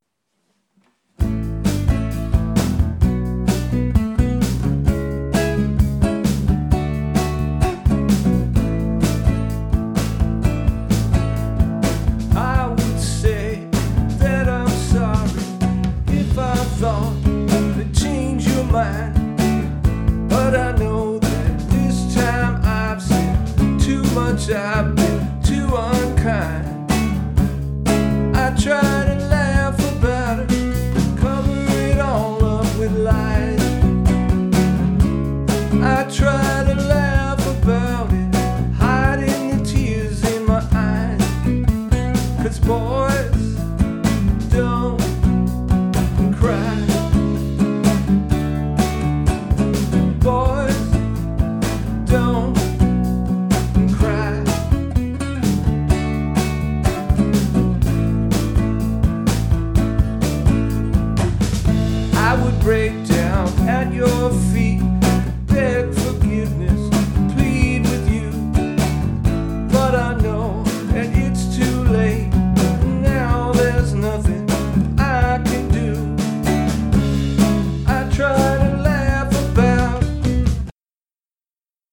Genre: Classic Rock.